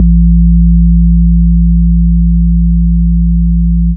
Bad Bass 65-09.wav